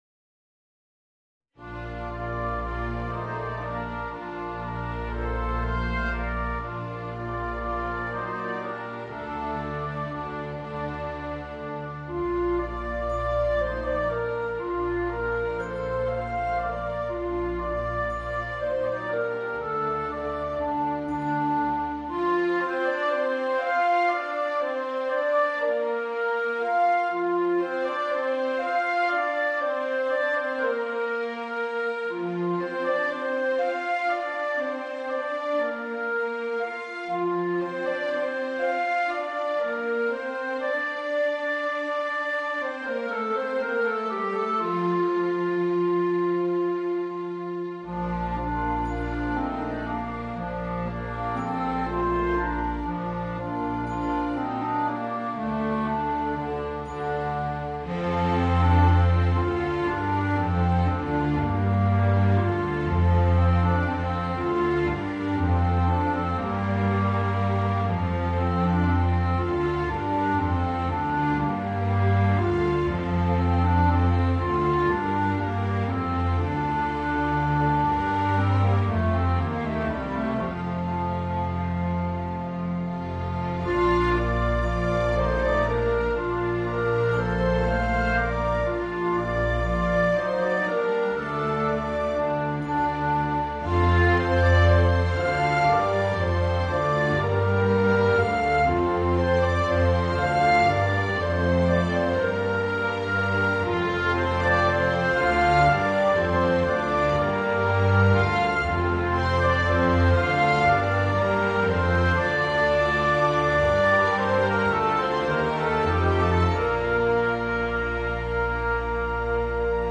Voicing: Violin and Orchestra